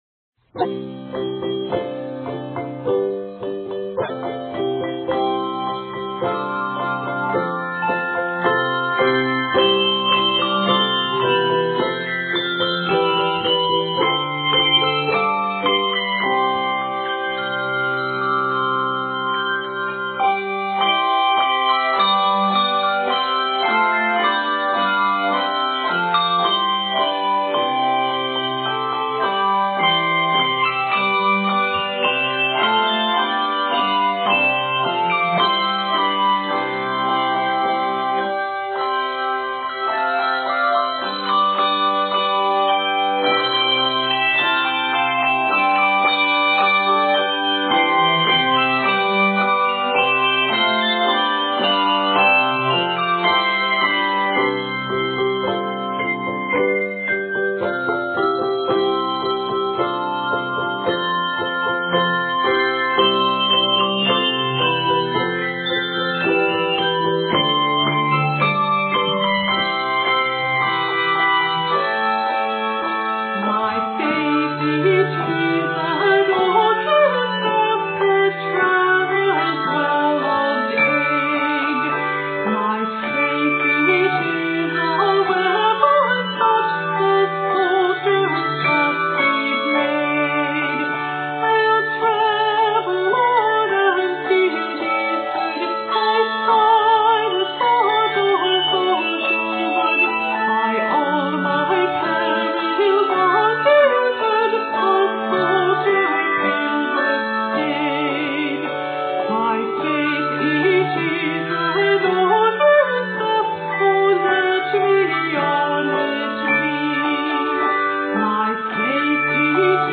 This piece is written in C and D Majors and is 104 measures.